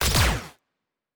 pgs/Assets/Audio/Sci-Fi Sounds/Weapons/Weapon 07 Shoot 2.wav at master
Weapon 07 Shoot 2.wav